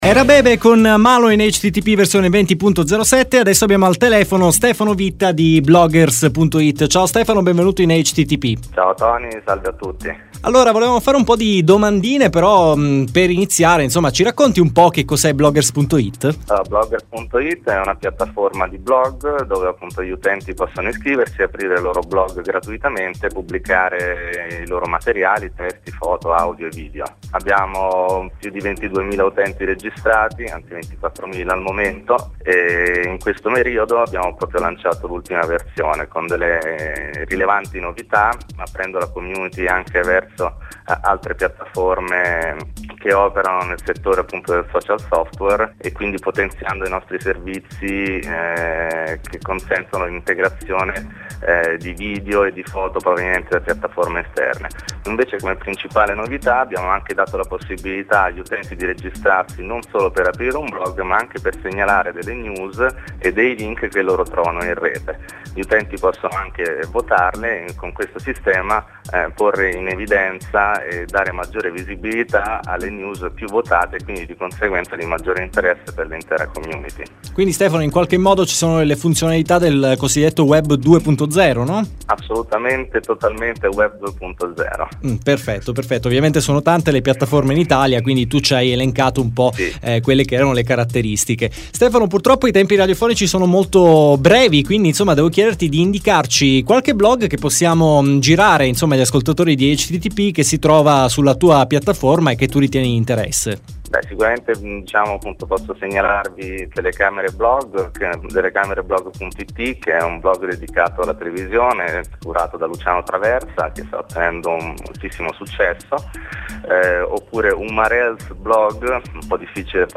Quest’anno (ottava stagione) ho intenzione di dare molto spazio ai blogger con interviste settimanali.